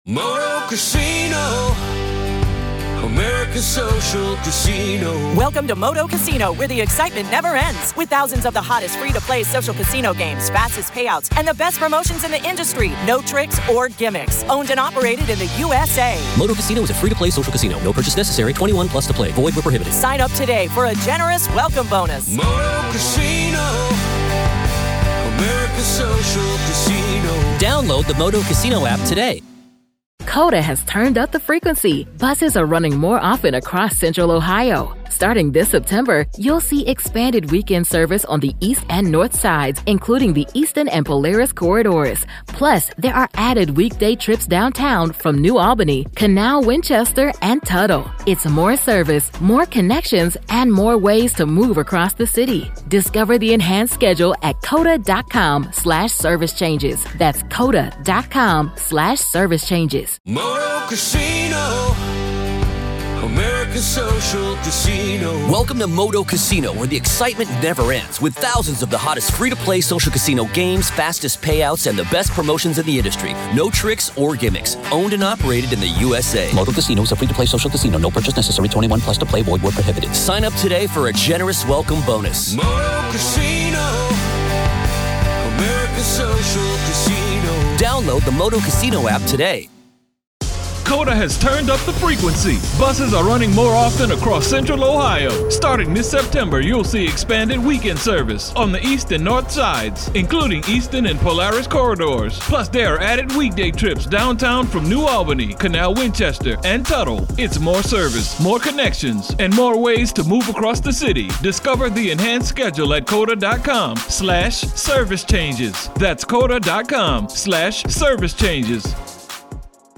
pivot from lighthearted fall banter